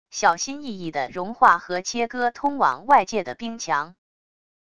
小心翼翼地融化和切割通往外界的冰墙wav音频